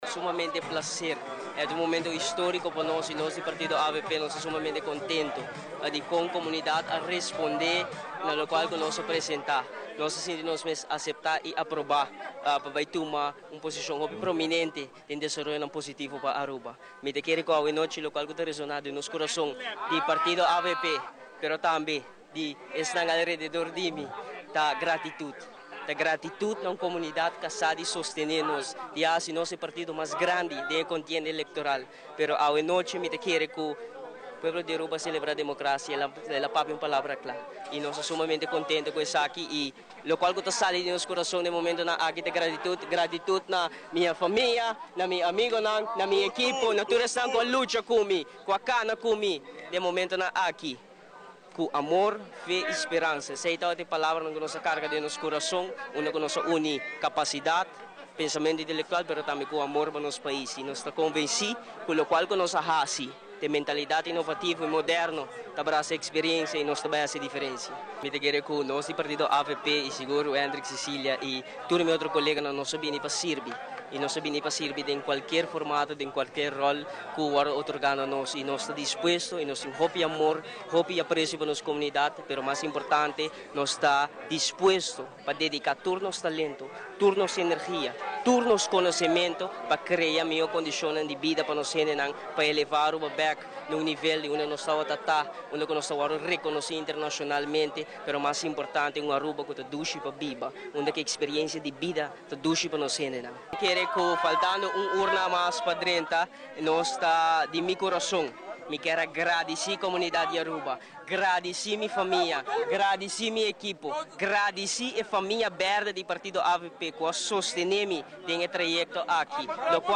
Tambe nos a combersa cu “lijsttrekker “ di partido AVP  Wendrick cecilia , y ta sumamente contento riba e resultado y sigur e ta un momento historico pa Aruba. Na mes momento ta gradici tur votado y ta sinti di ta acepta y aproba pa e pueblo di Aruba y na unda cu pueblo di Aruba a celebra democracia.